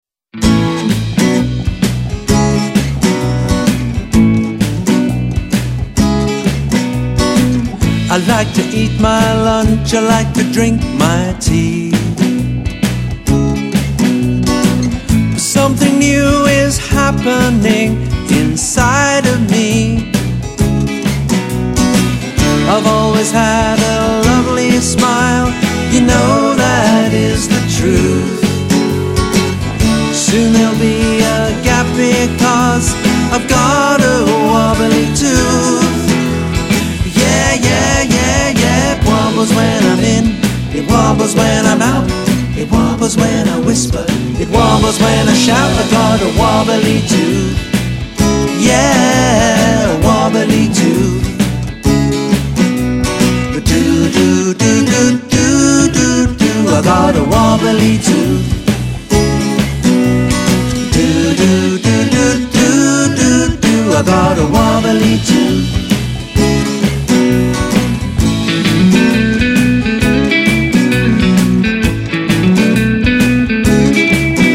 children's songs